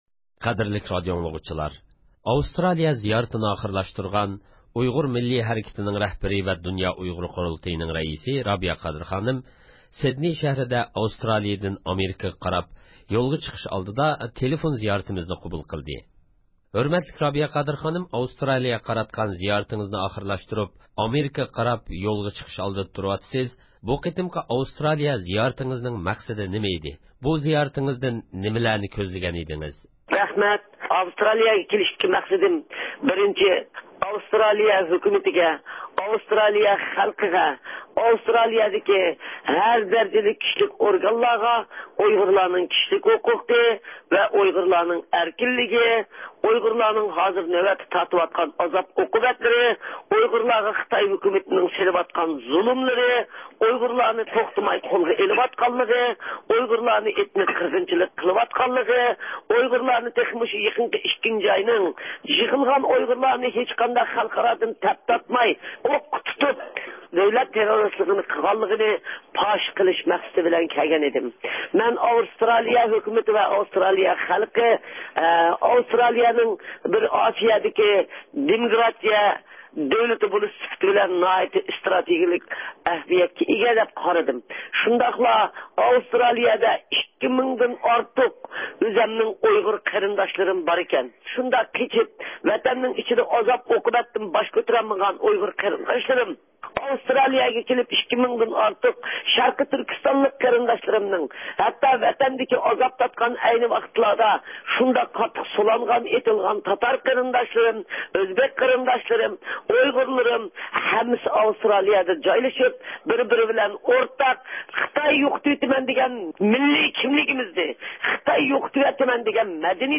ئاۋسترالىيە زىيارىتىنى ئاخىرلاشتۇرغان، ئۇيغۇر مىللىي ھەرىكىتىنىڭ رەھبىرى ۋە دۇنيا ئۇيغۇر قۇرۇلتىيىنىڭ رەئىسى رابىيە قادىر خانىم، سېدنىي شەھىرىدە، ئاۋسترالىيىدىن ئامېرىكىغا قاراپ يولغا چىقىش ئالدىدا، تېلېفون ئارقىلىق زىيارىتىمىزنى قوبۇل قىلدى.